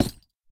Minecraft Version Minecraft Version 1.21.5 Latest Release | Latest Snapshot 1.21.5 / assets / minecraft / sounds / block / hanging_sign / break3.ogg Compare With Compare With Latest Release | Latest Snapshot
break3.ogg